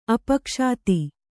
♪ apakṣāti